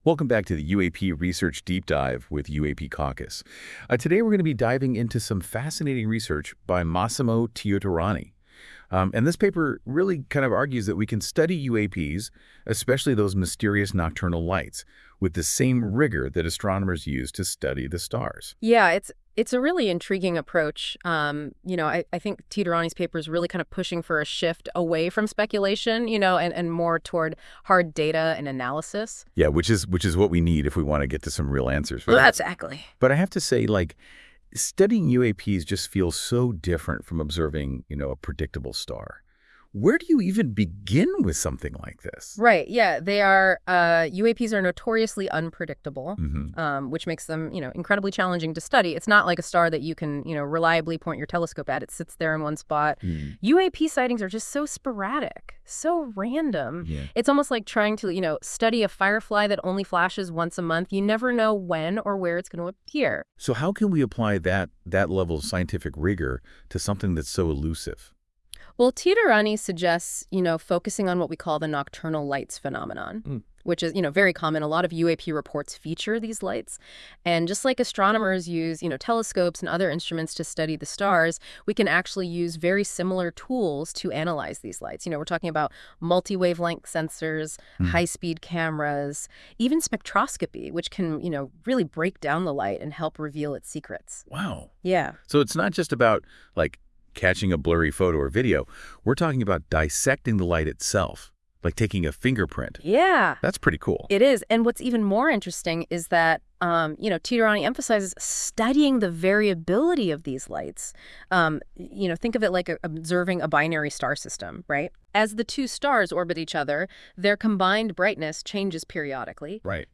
Powered by NotebookLM. This AI-generated audio may not fully capture the research's complexity.